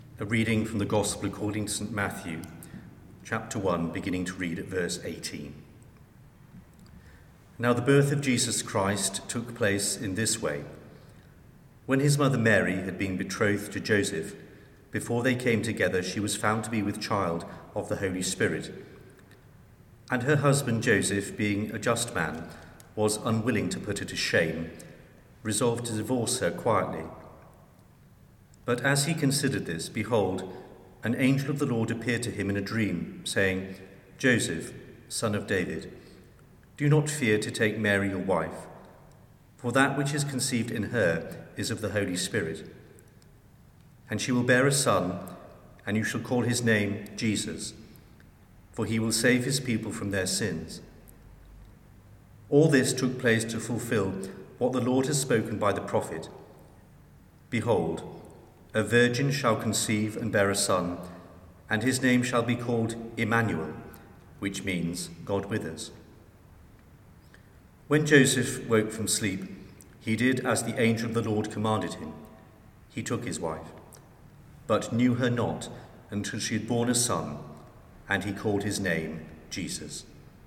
Advent readings – No. 2 – St Paul's
Advent-Reading-2.mp3